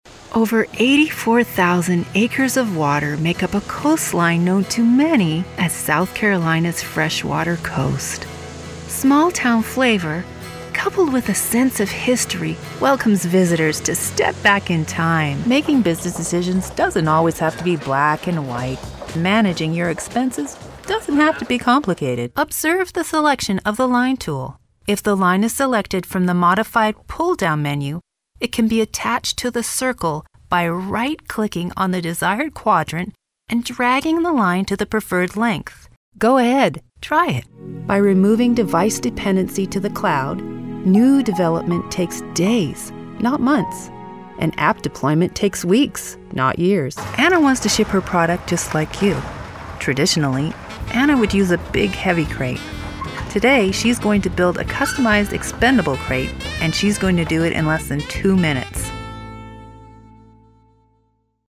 My voice has been described as..... Classic conversational. Professional. Articulate. Real and non selling. Warm. Natural and friendly. Sincere. Honest. Fun.
I have my own fully equipped high quality professional home studio, I provide broadcast-quality tracks on demand.
Adult, Mature Adult
Location: Atlanta, GA, USA Accents: canadian | natural standard us | natural Voice Filters: COMMERCIAL FILTER tv promos NARRATION FILTERS explainer video